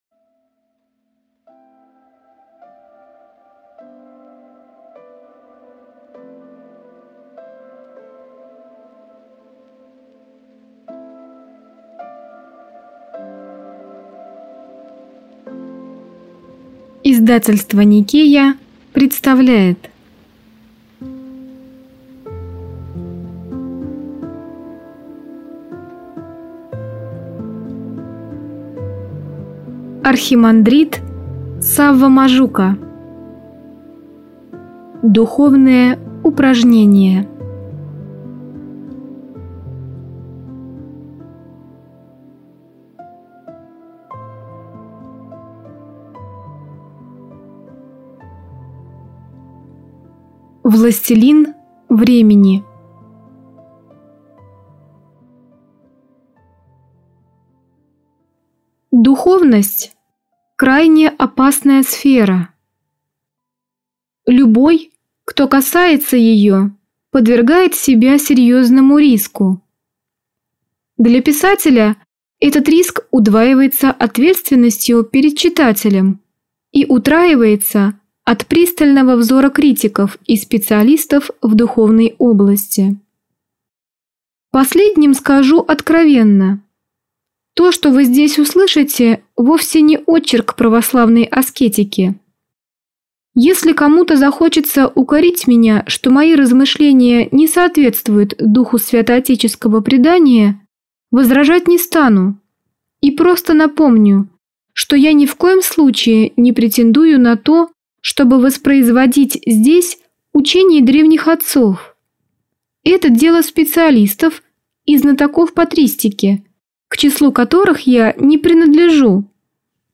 Аудиокнига Духовные упражнения | Библиотека аудиокниг